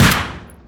sol_reklam_link sag_reklam_link Warrock Oyun Dosyalar� Ana Sayfa > Sound > Weapons > Mp5k Dosya Ad� Boyutu Son D�zenleme ..
WR_fire.wav